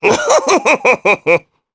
Oddy-Vox_Happy2_jp.wav